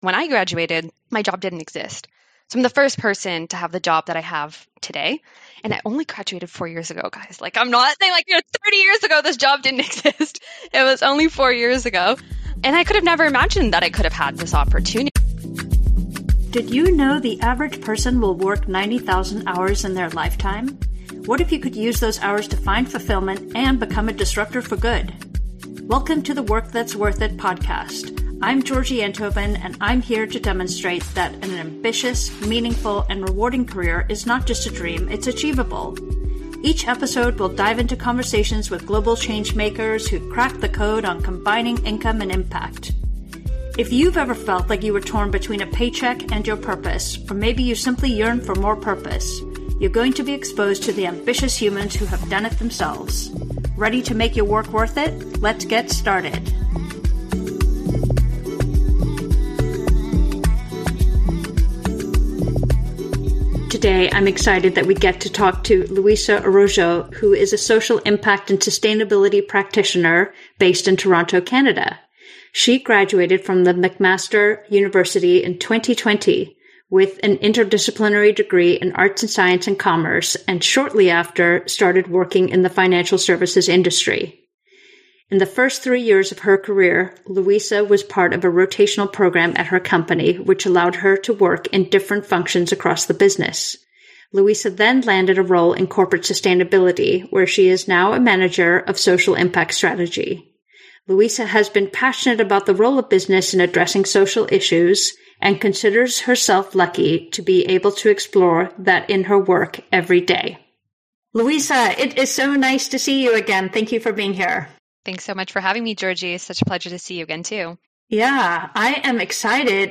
Listen in for an inspiring conversation that will leave you excited about the future of work and the generation that’s reshaping it.